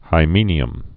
(hī-mēnē-əm)